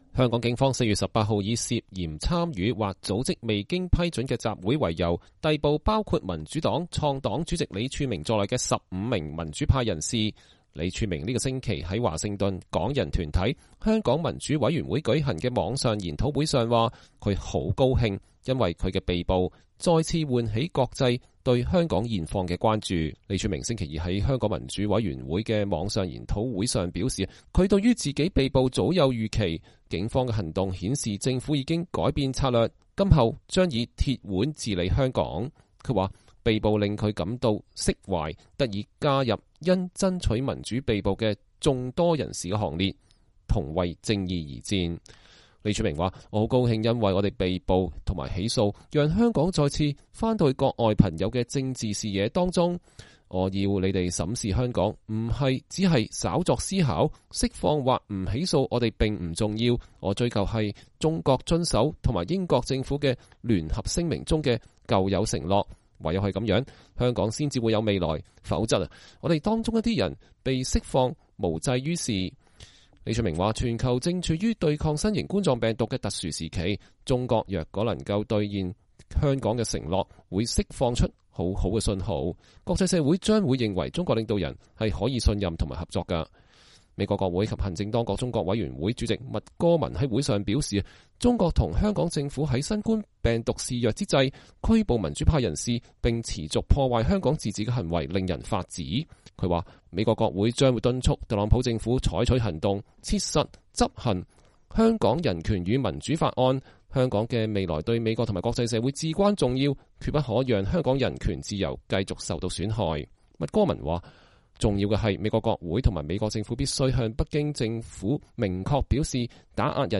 李柱銘星期二在香港民主委員會（HKDC）的線上研討會上表示，他對於自己被捕早有預期，警方的行動顯示政府已改變策略，今後將以“鐵腕”治理香港。